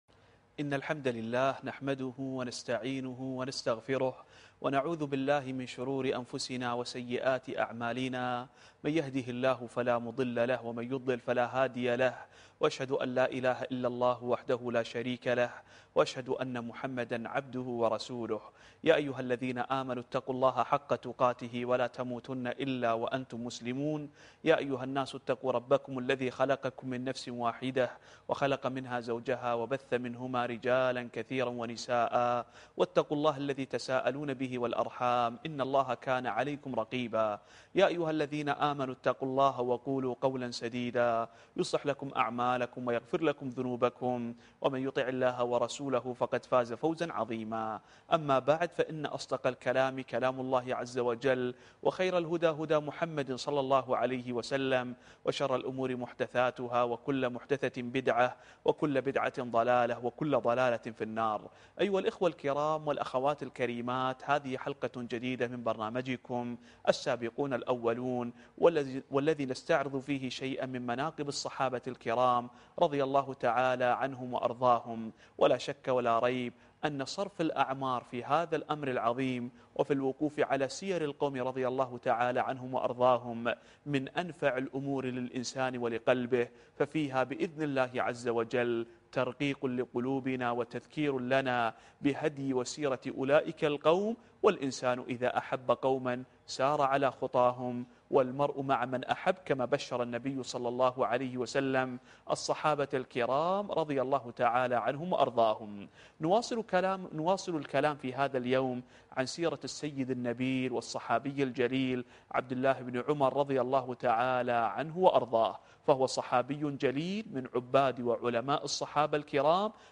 الدرس الخامس والعشرون